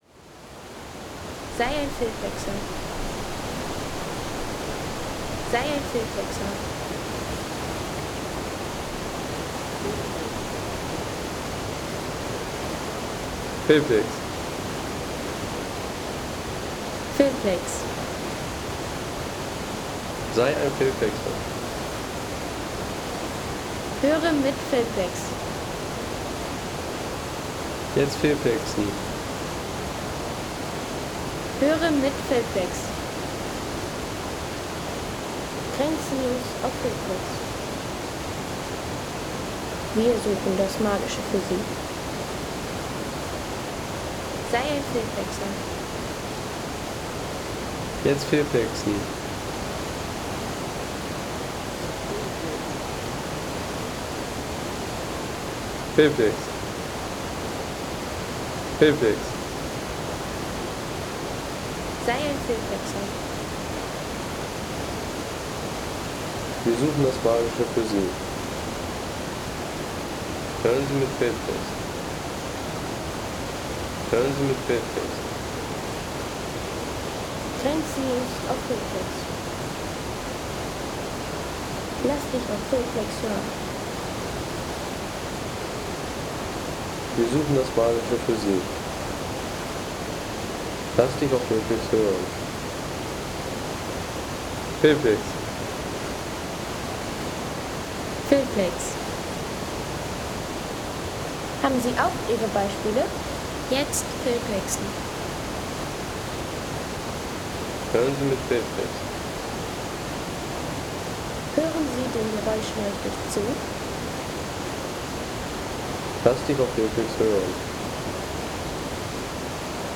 Isel bei Ainet Home Sounds Landschaft Flüsse Isel bei Ainet Seien Sie der Erste, der dieses Produkt bewertet Artikelnummer: 190 Kategorien: Landschaft - Flüsse Isel bei Ainet Lade Sound.... Wildwasserfreuden am Ufer der Isel bei Adventurepark.